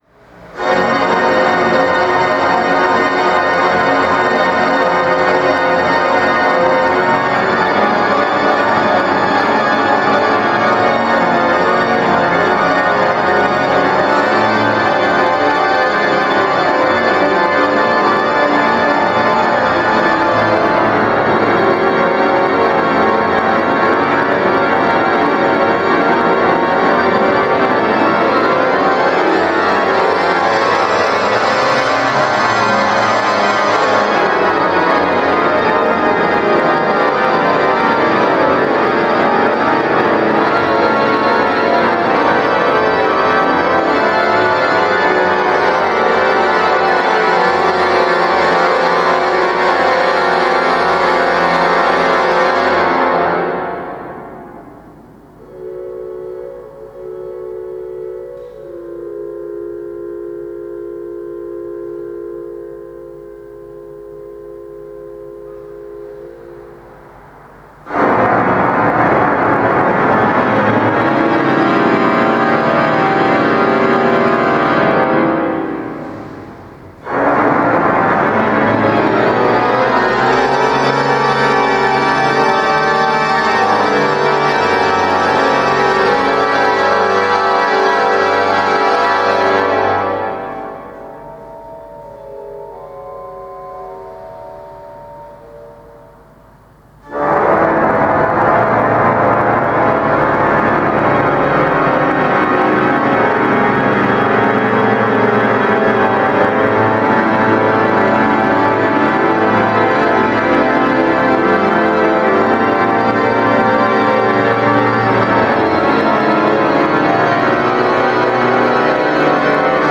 Improvisations sur des cantiques de Noël
Concert donné en l’église Saint-André de l’Europe (Paris 8),